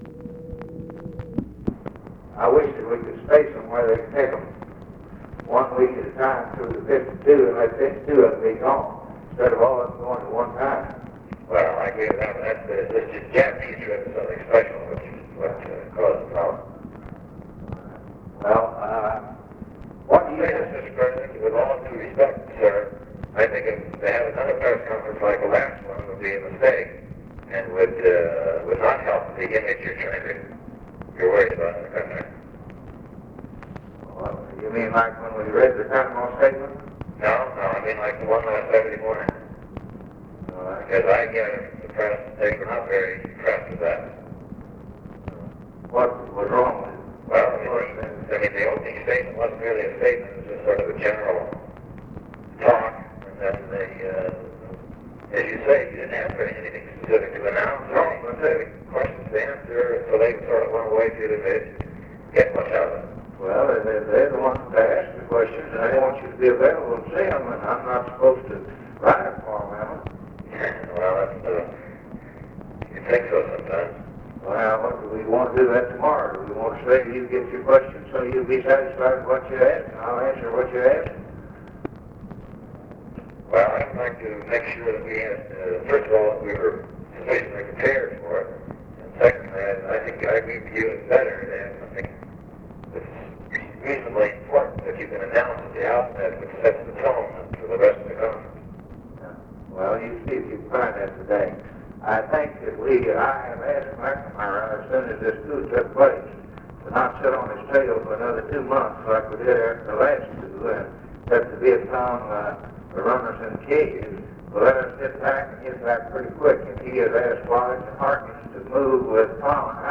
Conversation with (possibly) PIERRE SALINGER, January 31, 1964
Secret White House Tapes